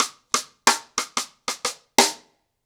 Wireless-90BPM.17.wav